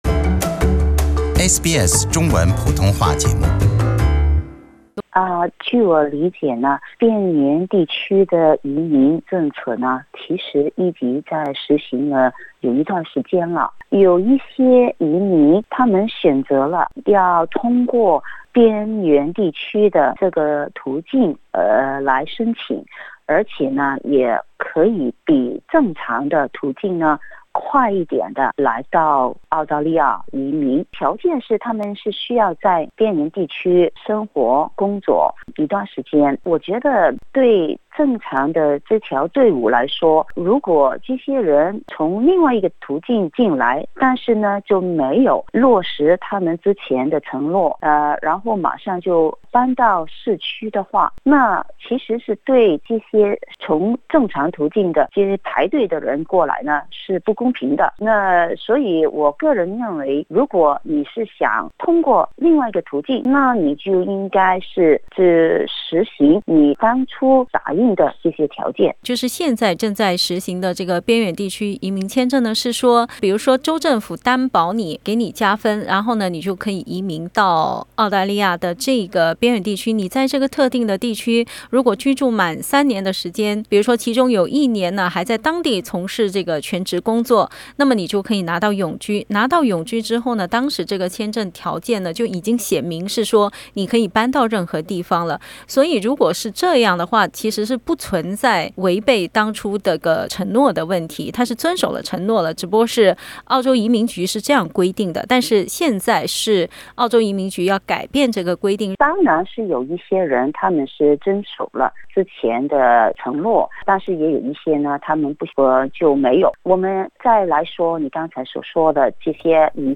她接受SBS普通话节目采访。